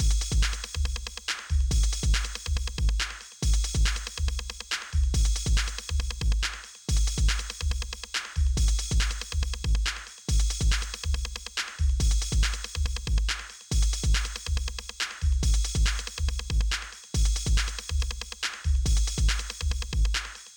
It will run fine for 1 or 2 bars and then you can hear it play off time.